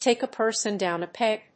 アクセントtàke a person dówn a pég (or twó)